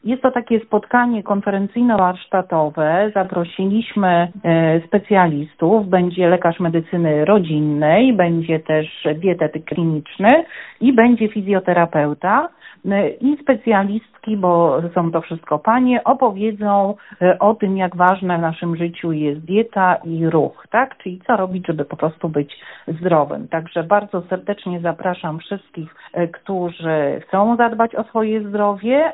– „Relacja z Twoim zdrowiem” – to temat pierwszego spotkania, które odbędzie się już w najbliższą sobotę (11.04) w EkoMarinie, mówi Radiu 5 Ewa Ostrowska, burmistrz Giżycka, która objęła wydarzenie swoim patronatem.